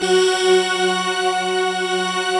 Index of /90_sSampleCDs/Optical Media International - Sonic Images Library/SI1_Breath Choir/SI1_Breathoctave